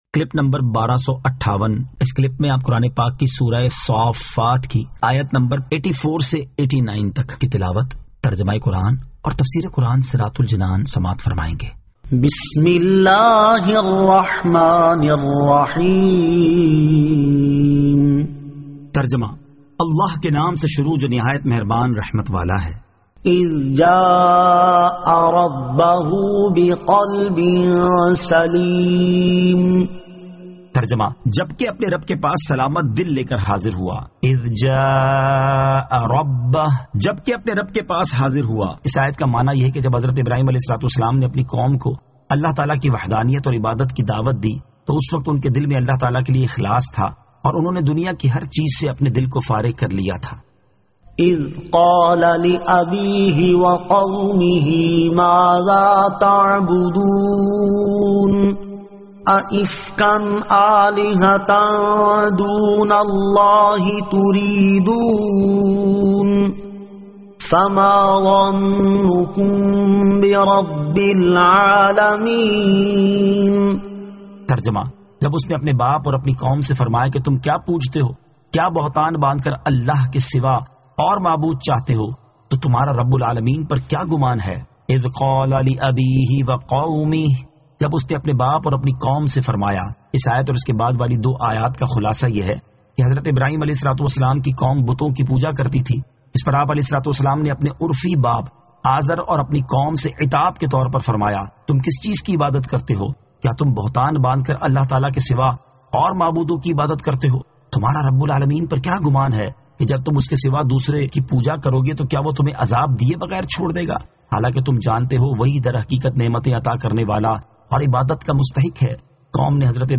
Surah As-Saaffat 85 To 89 Tilawat , Tarjama , Tafseer